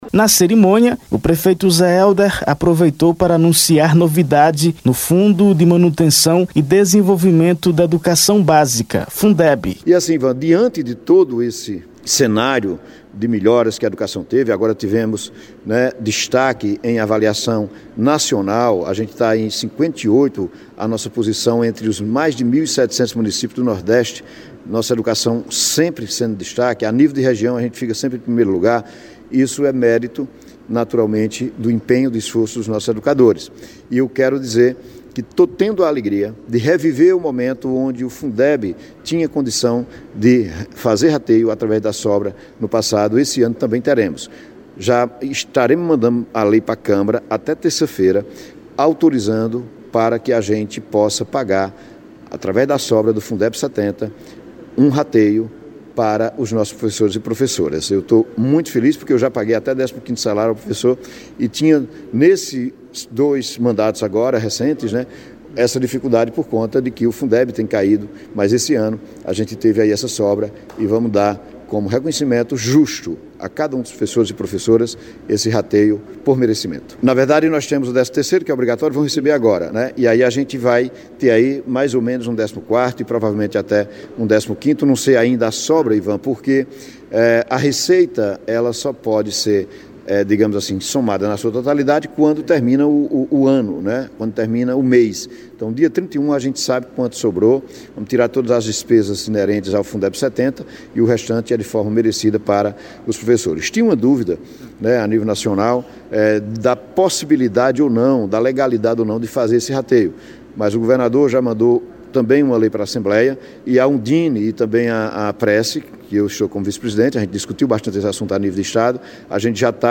O anúncio ocorreu na manhã deste sábado, 04, no auditório da escola municipal Dr. Pedro Sátiro, no Centro.